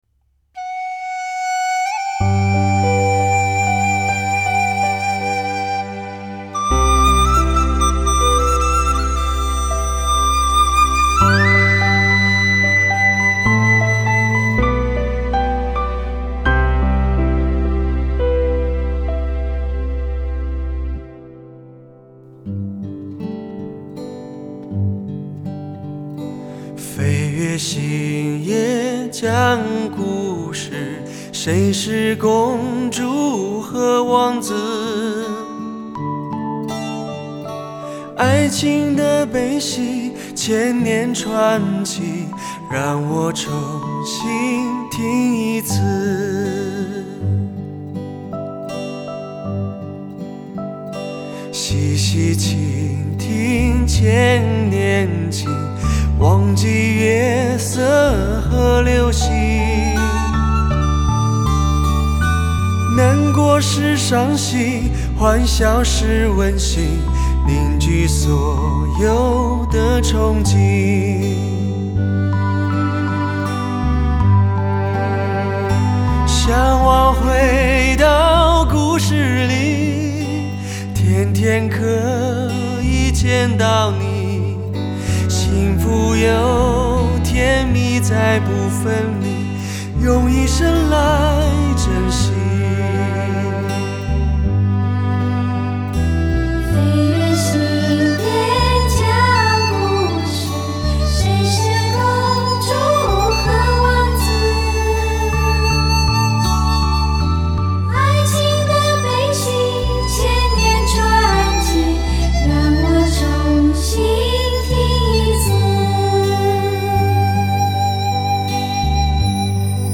超值精品 DSD